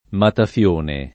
vai all'elenco alfabetico delle voci ingrandisci il carattere 100% rimpicciolisci il carattere stampa invia tramite posta elettronica codividi su Facebook matafione [ mataf L1 ne ] (raro mataffione [ mataff L1 ne ] o mattafione [ mattaf L1 ne ]) s. m. (mar.)